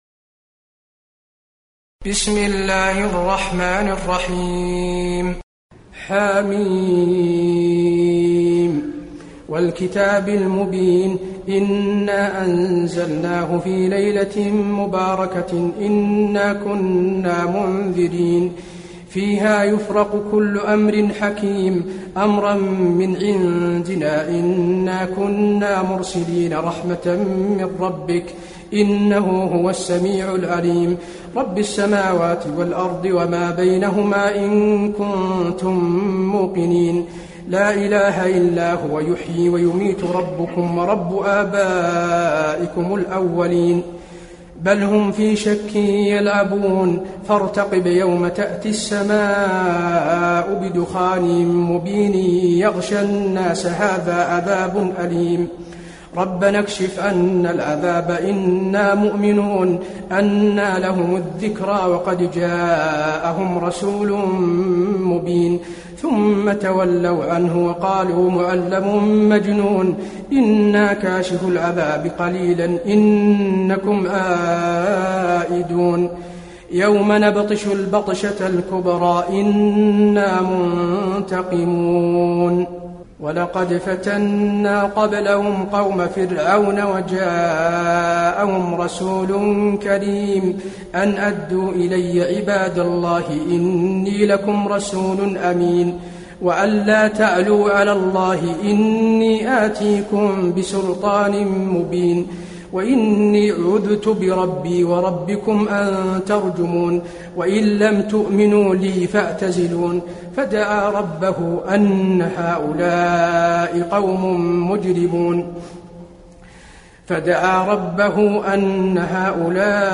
المكان: المسجد النبوي الدخان